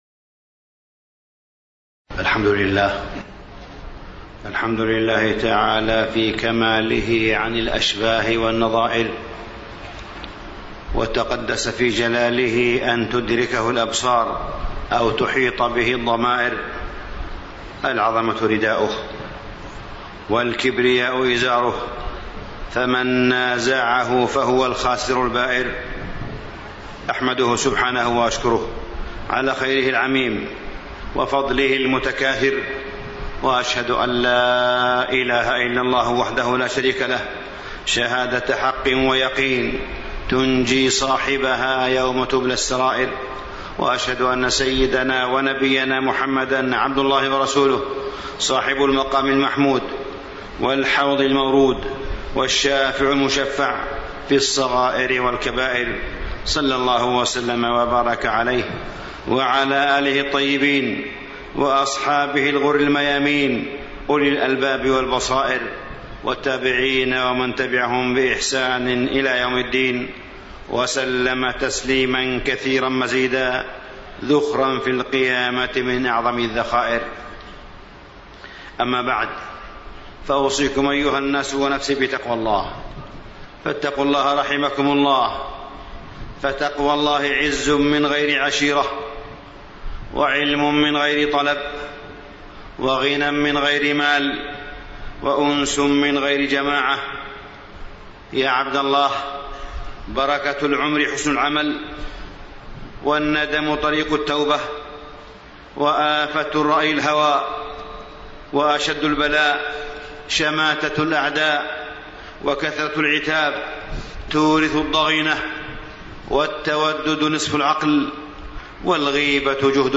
تاريخ النشر ١٠ رجب ١٤٣٥ هـ المكان: المسجد الحرام الشيخ: معالي الشيخ أ.د. صالح بن عبدالله بن حميد معالي الشيخ أ.د. صالح بن عبدالله بن حميد التقاعد والعمل للإسلام